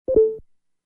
SFX – FACEBOOK 2
SFX-FACEBOOK-2.mp3